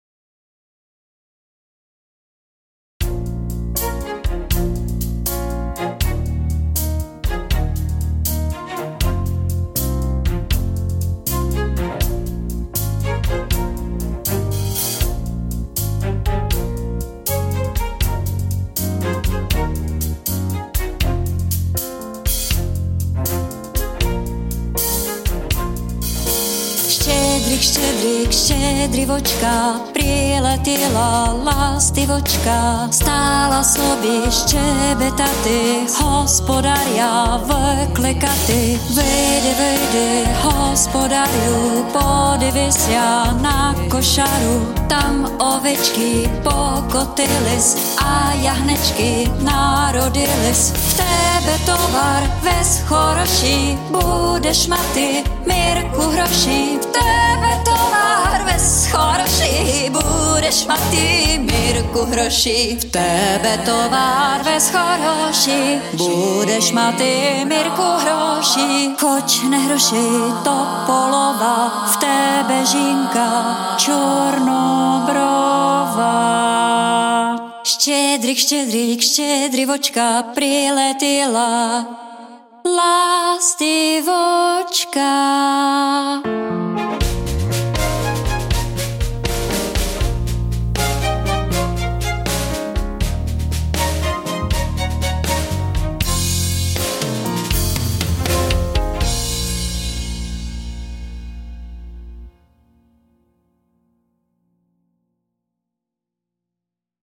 Žánr: World music/Ethno/Folk